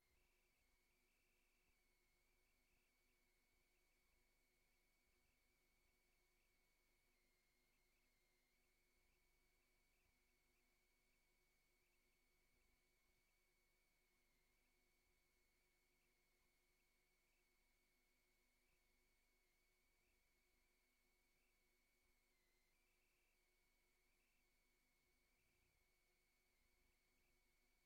Hoorzitting 08 maart 2022 18:30:00, Gemeente Woerden
Hoorzitting 'bezwaarschriftencommissie parkeerbeleid'